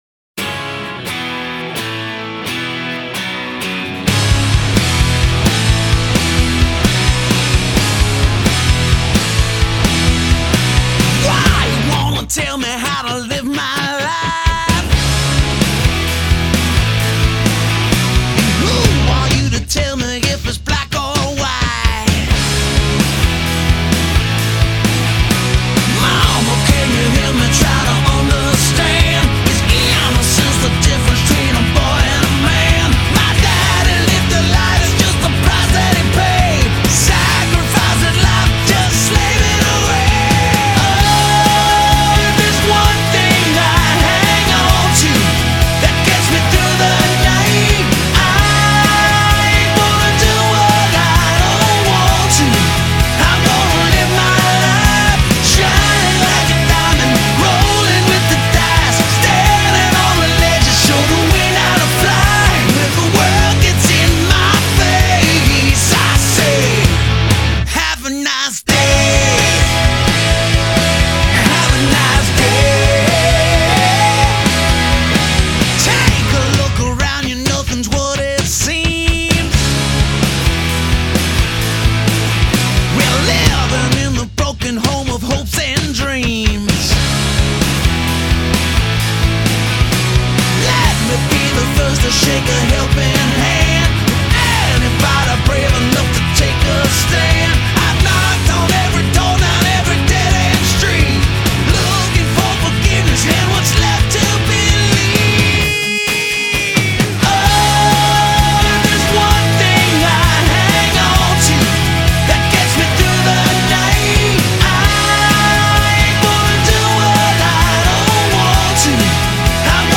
摇滚